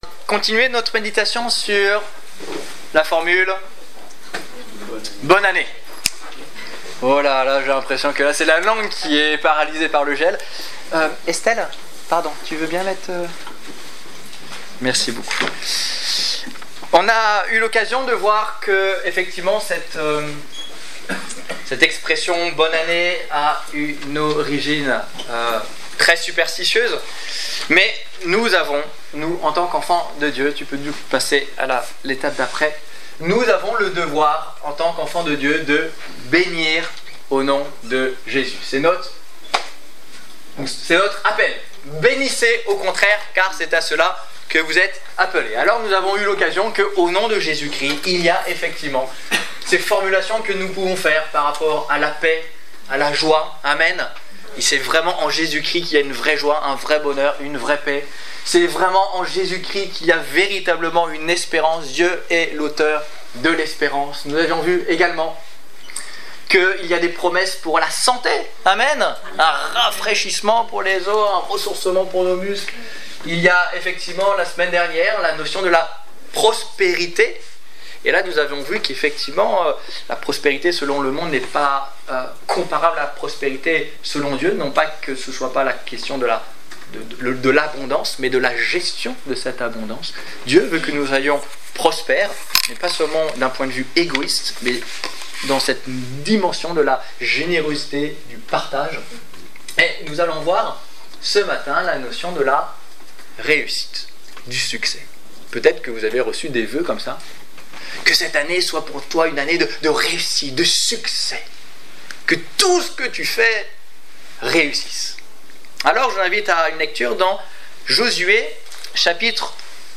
Exhortation - Culte du 17 janvier 2016